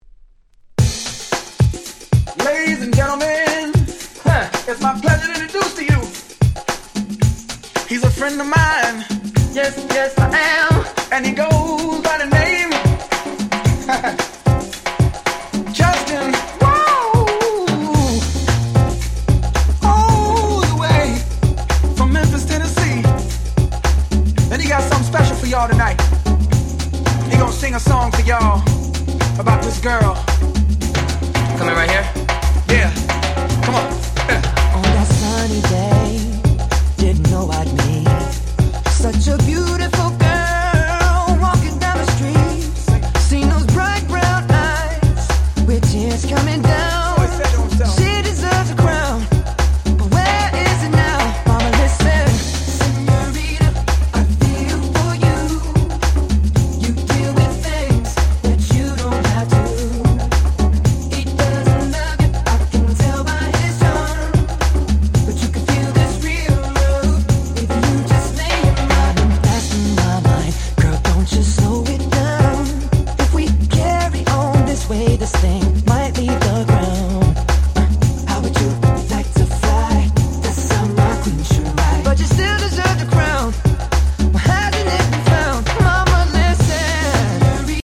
03' Super Hit R&B !!
オシャレだし踊れるしもう言う事無し！！
00's キャッチー系